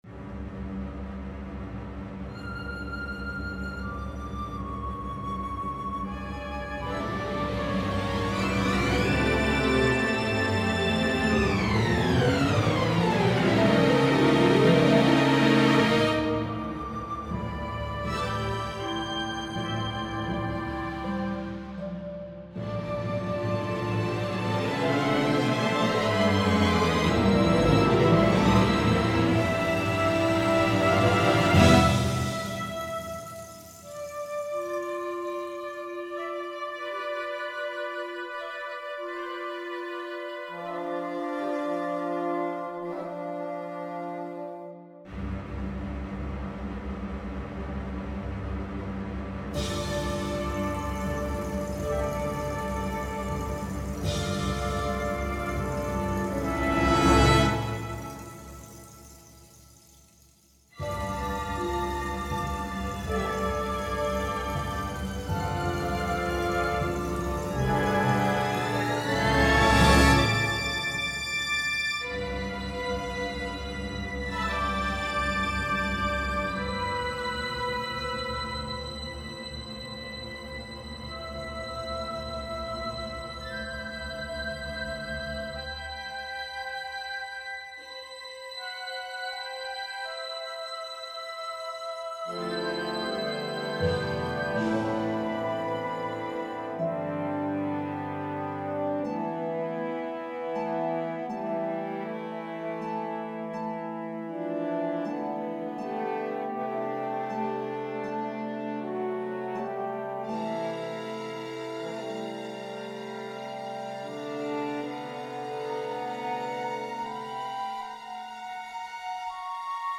What Is Left After the War? is a thirteen-minute composition for orchestra and amplified native soprano recorder, conceived as a transformational work that explores personal and collective trauma through musical expression. Drawing on ethnomusicological research, the piece integrates elements from Venezuelan milking songs (cantos de ordeño) and indigenous war calls from the plains (sabanas), reinterpreted through contemporary academic frameworks.